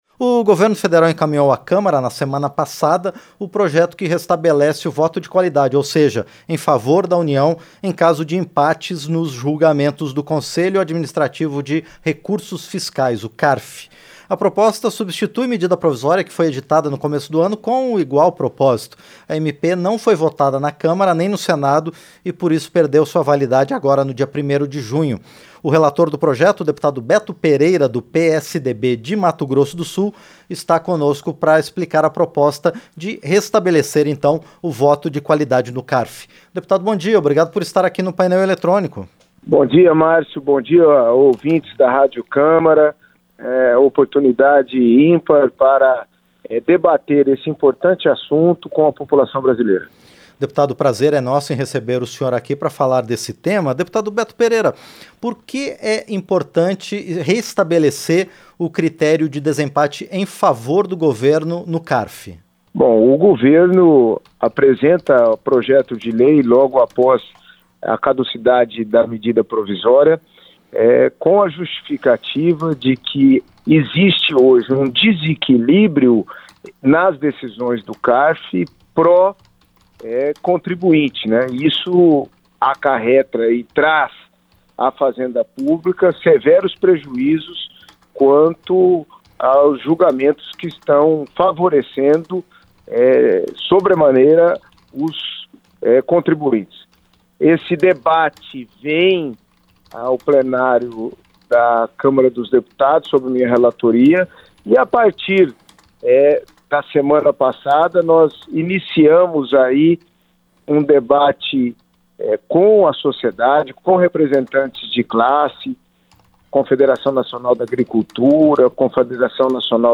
Relator adianta, em entrevista à Rádio Câmara, que ainda pretende recolher sugestões das bancadas partidárias
Em entrevista à Rádio Câmara nesta segunda-feira (21), Beto Pereira adiantou que pretende, a partir da apresentação do seu parecer, recolher sugestões das bancadas partidárias.